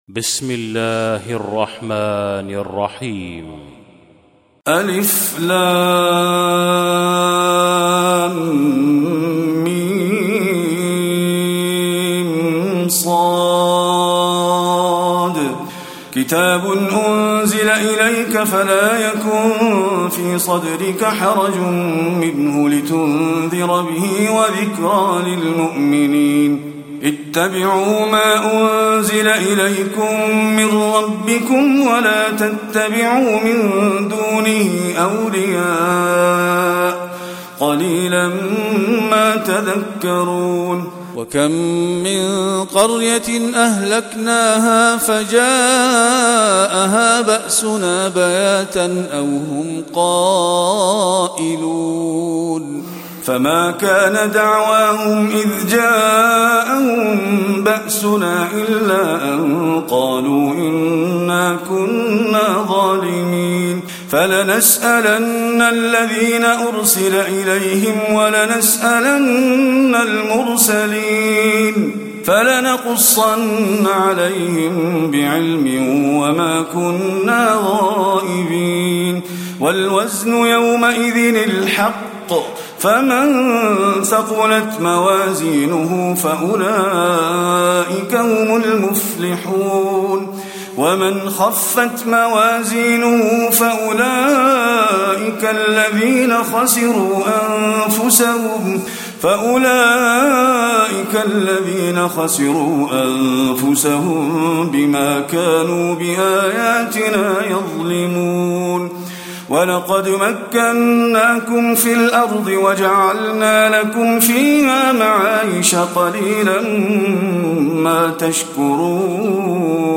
تلاوة سورة الأعراف من آية 1 إلى آية 64
تاريخ النشر ١ محرم ١٤٣٧ هـ المكان: المسجد النبوي الشيخ: فضيلة الشيخ محمد خليل القارئ فضيلة الشيخ محمد خليل القارئ سورة الأعراف (1-64)